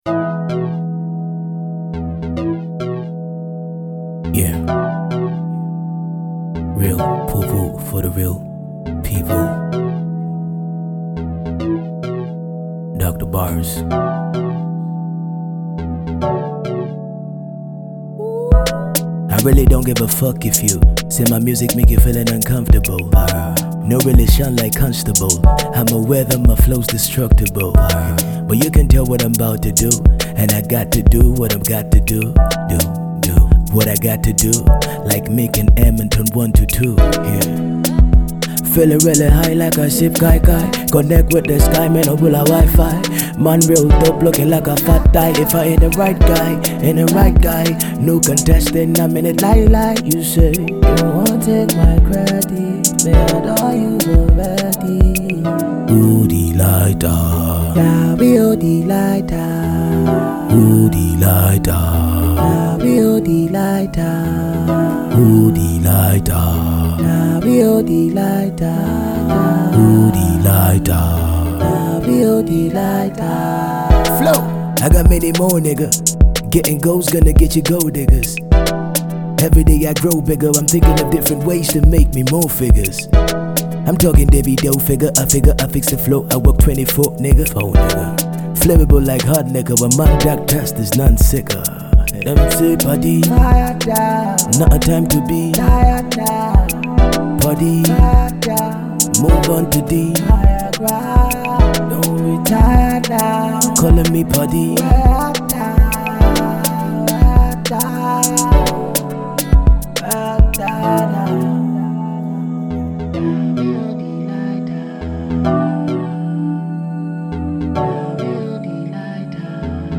Nigeria rapper
an alternative magical bliss